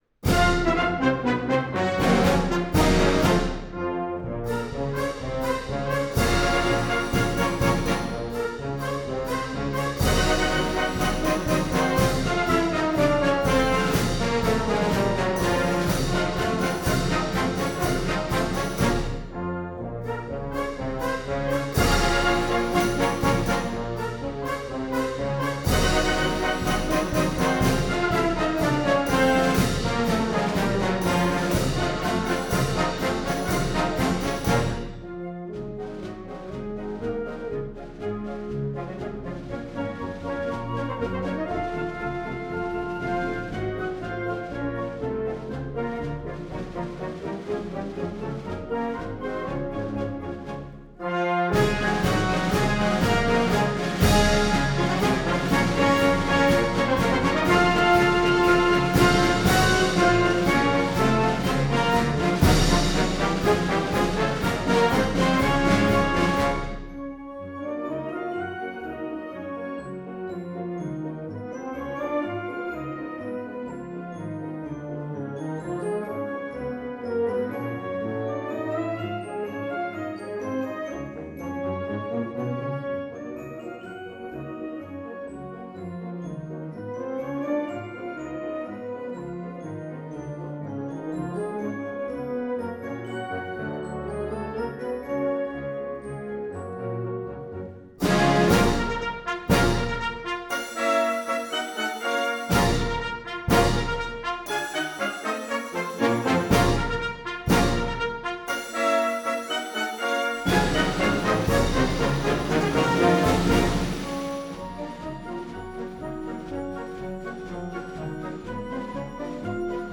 Nobles of the Mystic Shrine March from The Complete Marches of John Philip Sousa: Vol. 6